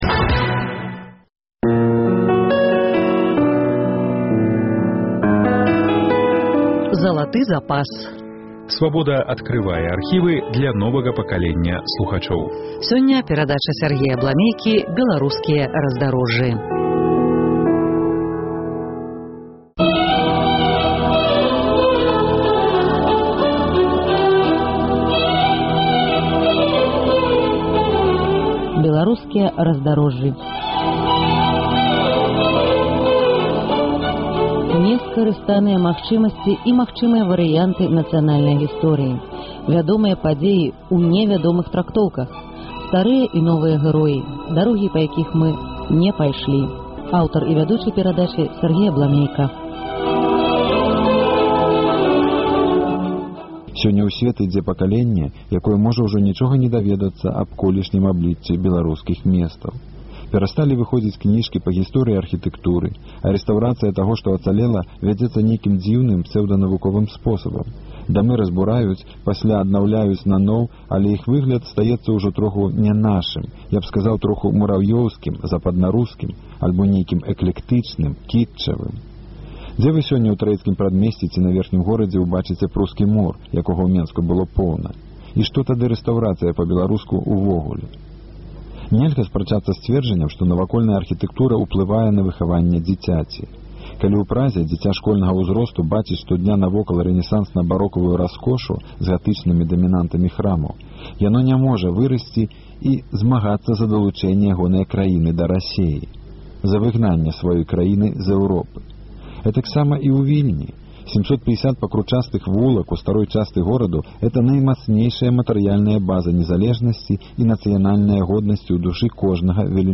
З радыёархіву Свабоды. "Беларускія раздарожжы", запіс 1999 году